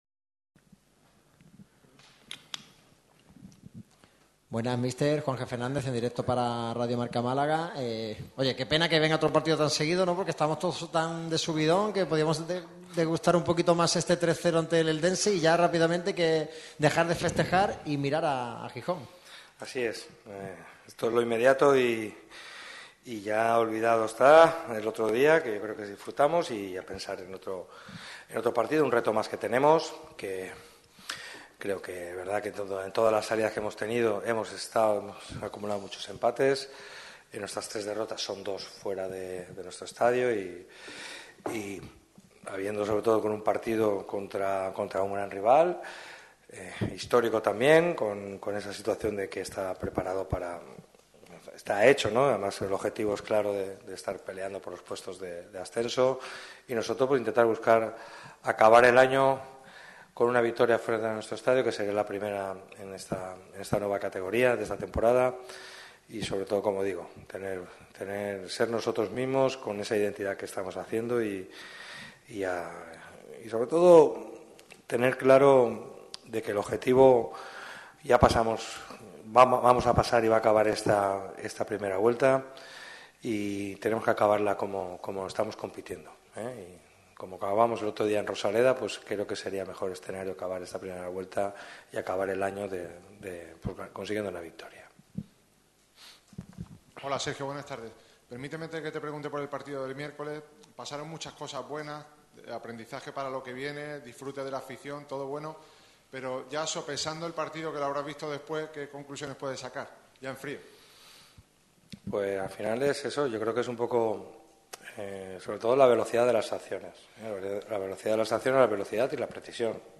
Esto ha comentado el técnico en sala de prensa tras el partido.